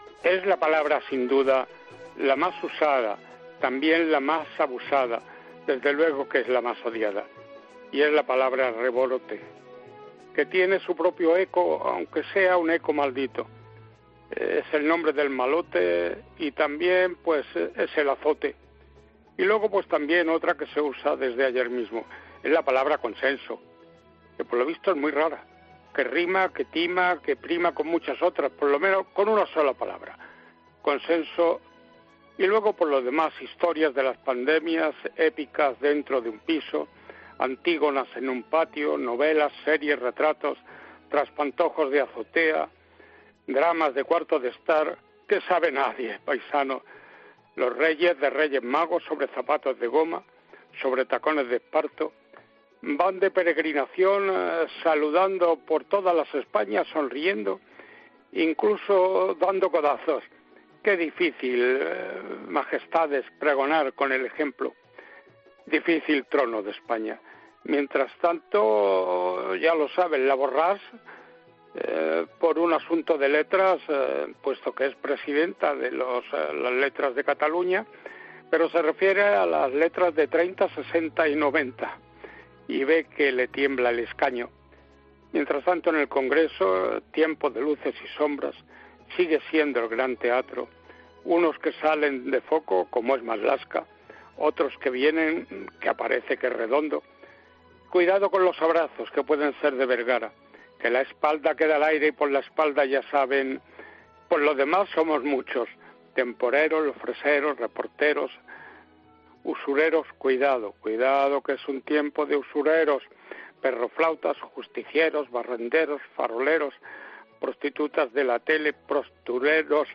No te pierdas el repaso a la actualidad de la semana de la mano del veterano periodista